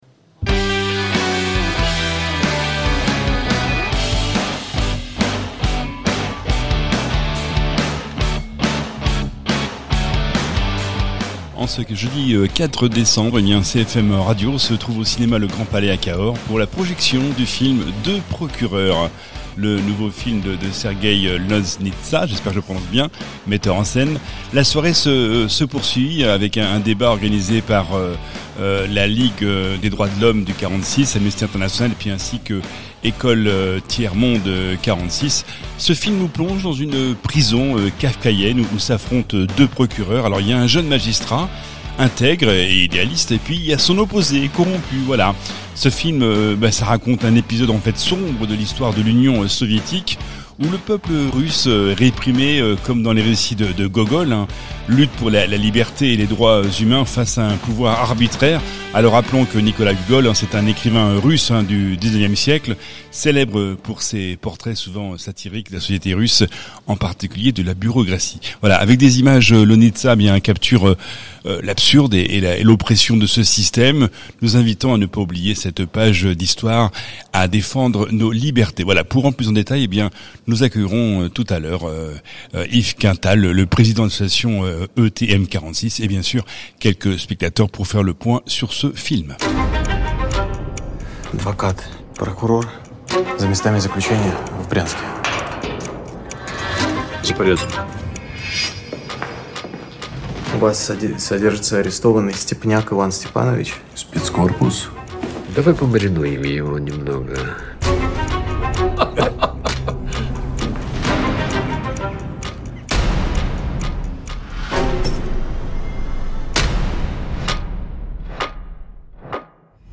Ciné-débat